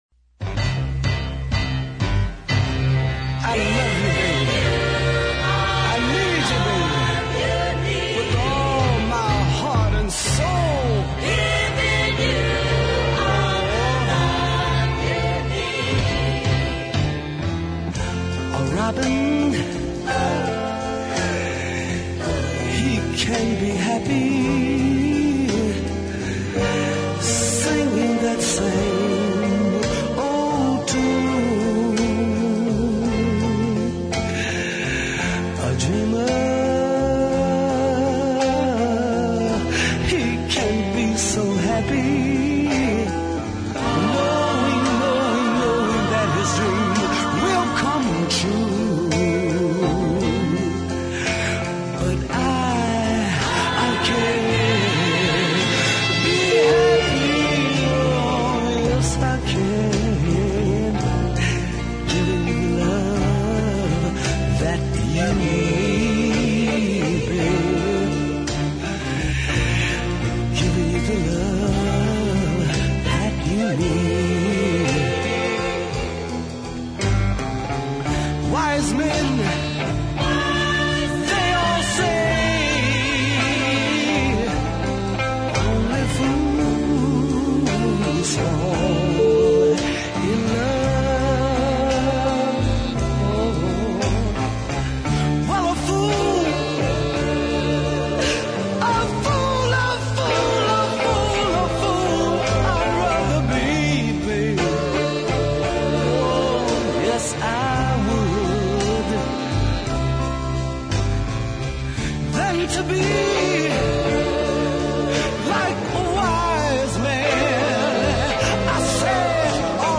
Check out the horns for example – just wonderful.